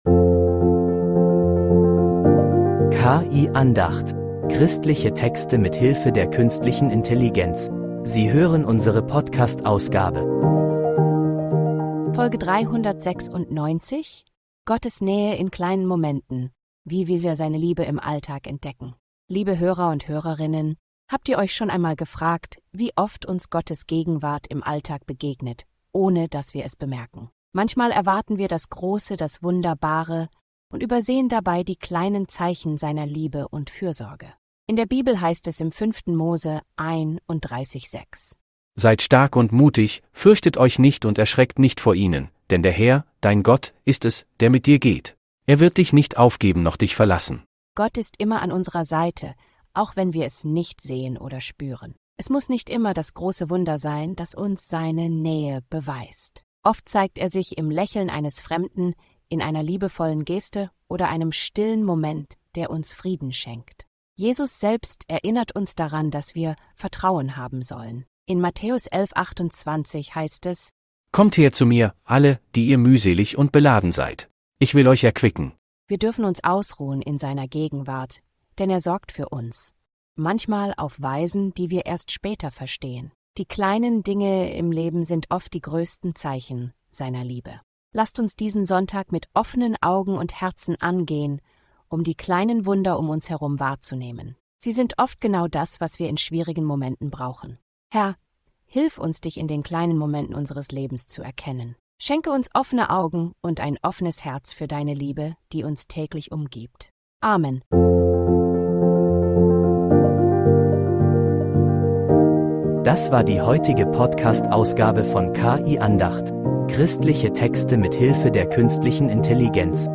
KI-Andacht